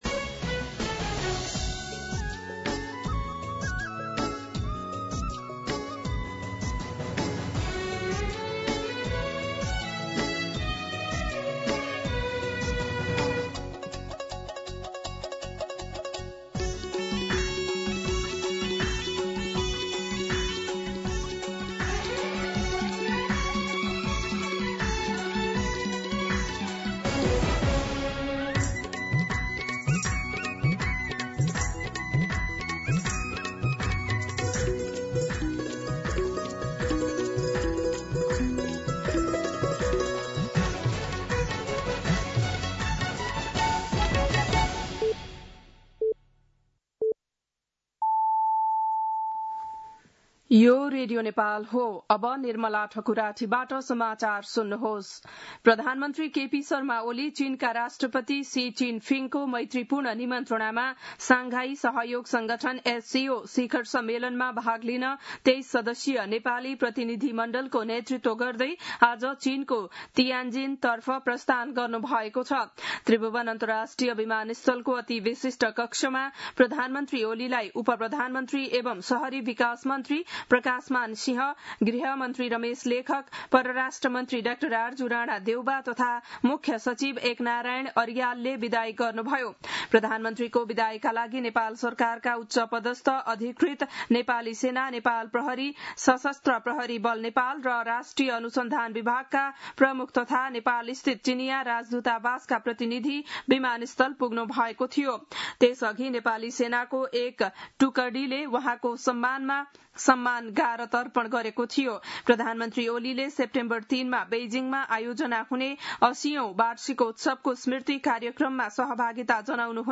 बिहान ११ बजेको नेपाली समाचार : १४ भदौ , २०८२
11-am-Nepali-News-7.mp3